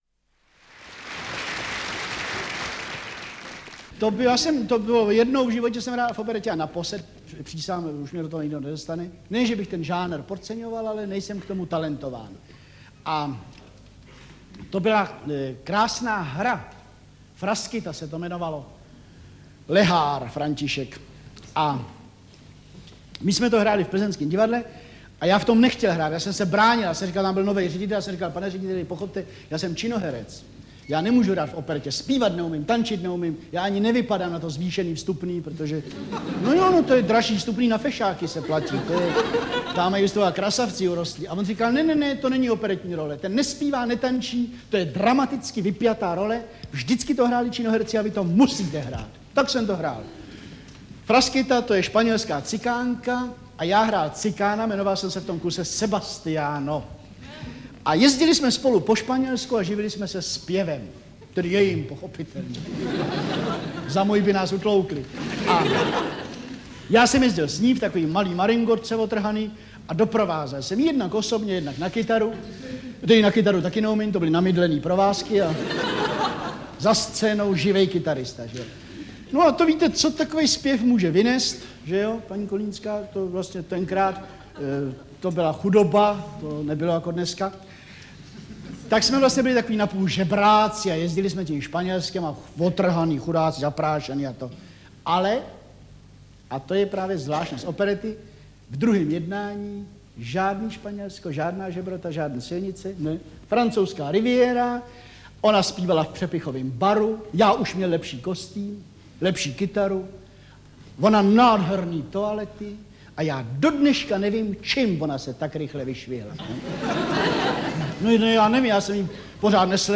Výběr ze vzpomínek vyprávěných při setkávání se Miroslava Horníčka se svým publikem v živě nahrávaných televizních pořadech vydal Supraphon v roce 1973. Vyprávění o tom jak hrál v operetě, jak cestoval, jak byl obsazen do Prodané nevěsty a mnoho dalších milých a veselých až rozverných příhod si nyní lze poslechnout poprvé digitálně.